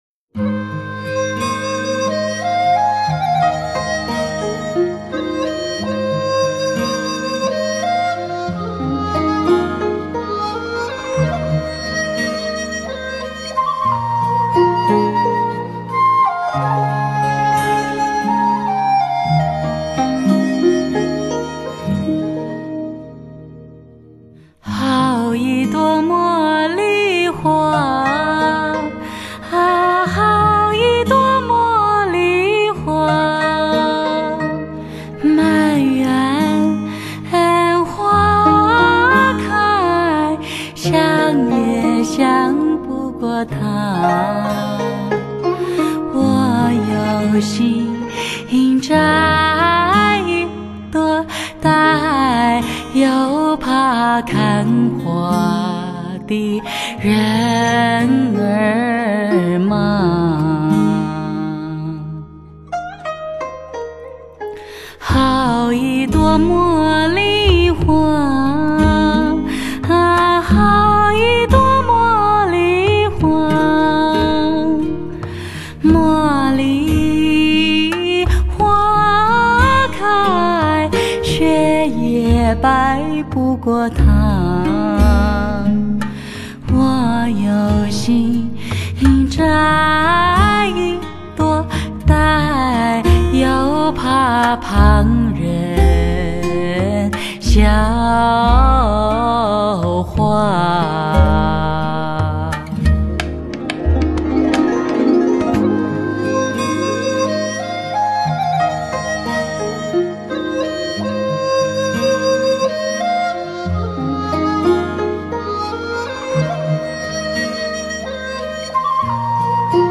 其具有民美，又兼具民通的浑然歌喉，婉约淡醇又内涵丰富的穿透力令同行和听众为此一一倾倒其中